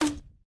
launcher2.ogg